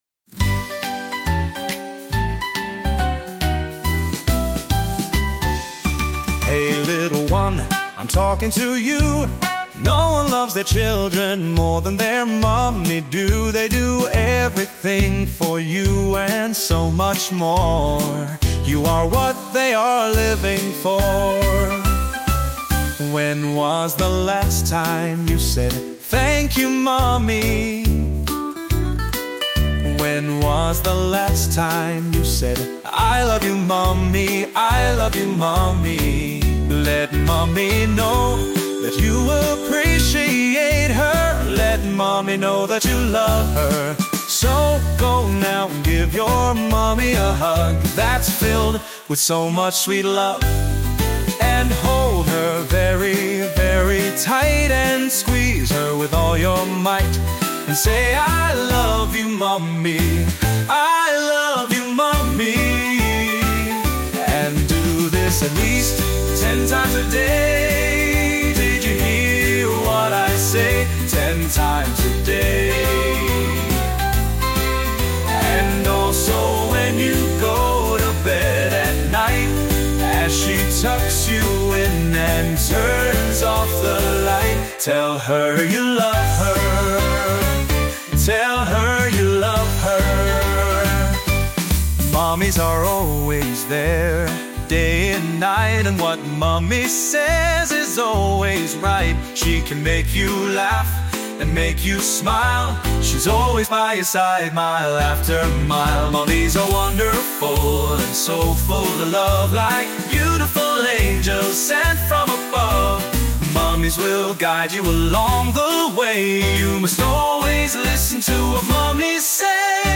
heartwarming and playful children’s song